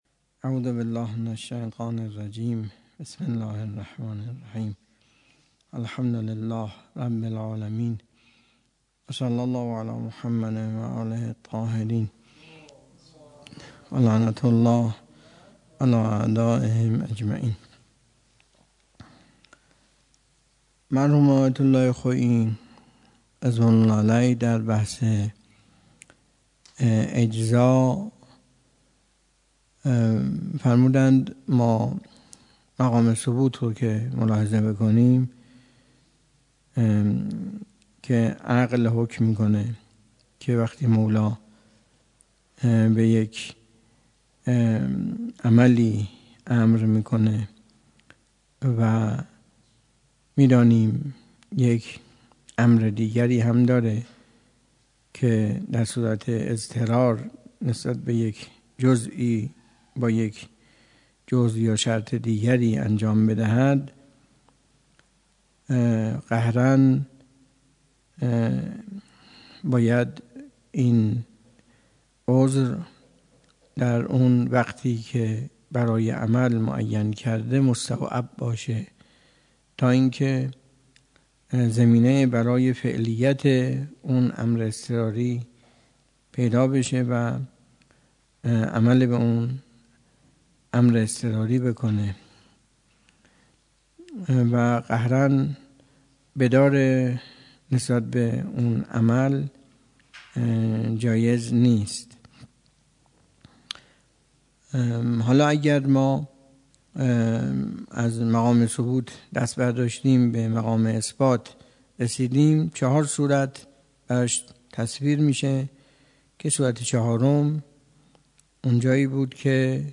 درس خارج اصول آیت الله تحریری - تاریخ 97.09.06